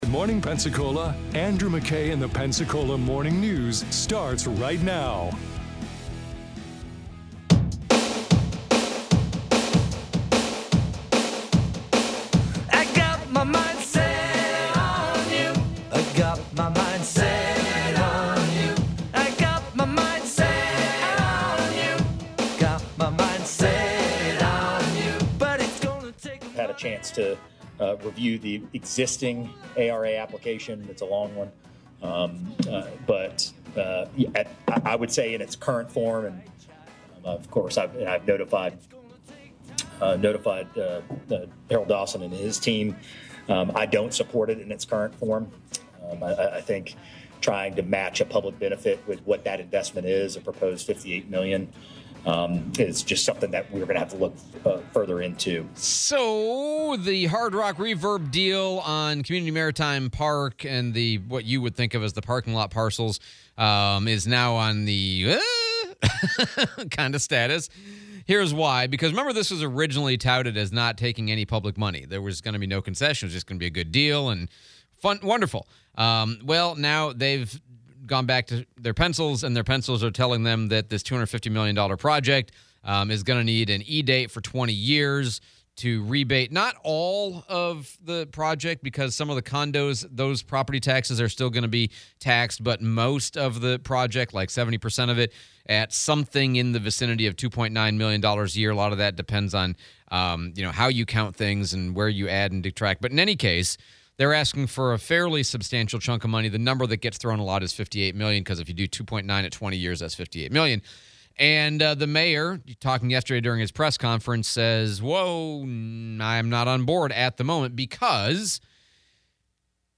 City of Pensacola Mayor press conference